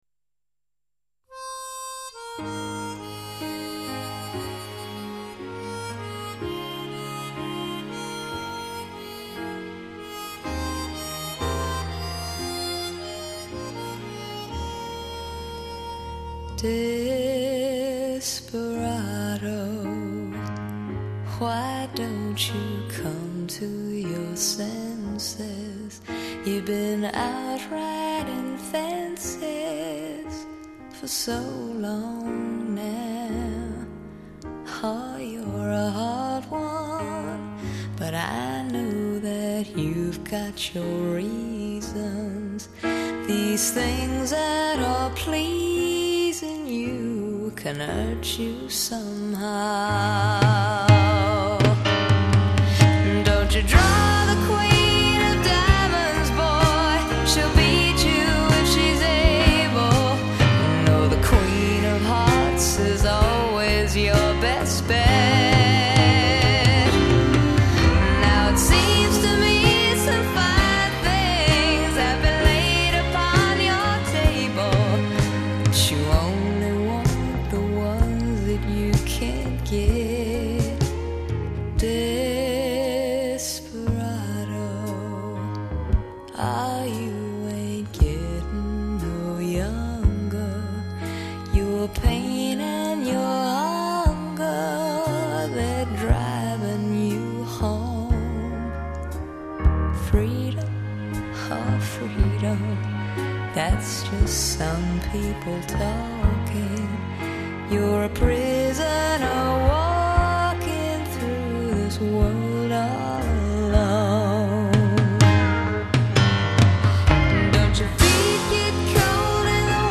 人声
钢琴